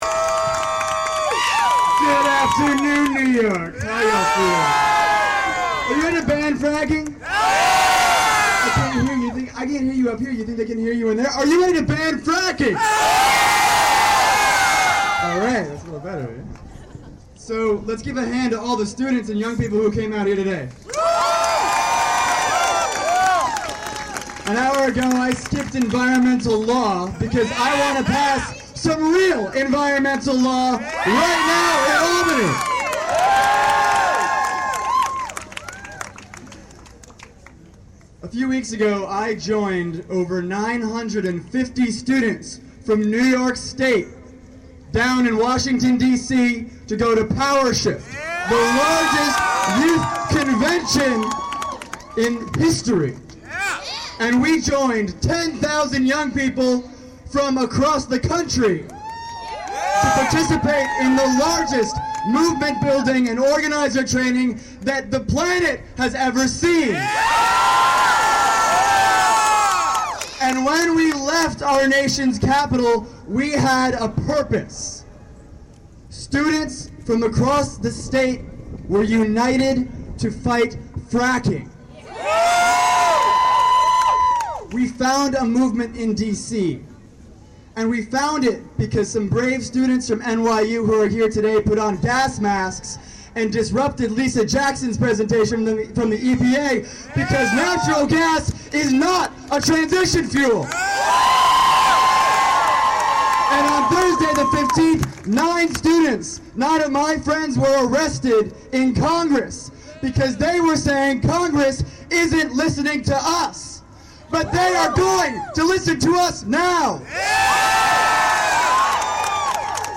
Speaking about hydraulic gas fracturing.